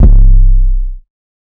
Drama 808
Drama-808.wav